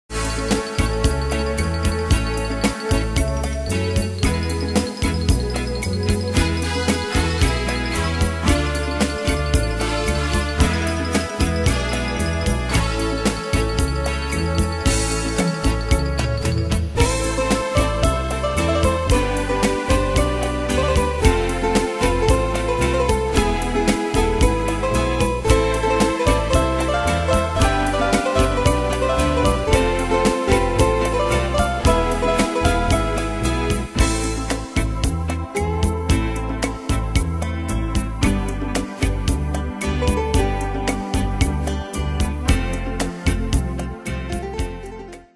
Style: PopFranco Ane/Year: 1990 Tempo: 113 Durée/Time: 4.01
Danse/Dance: Rhumba Cat Id.
Pro Backing Tracks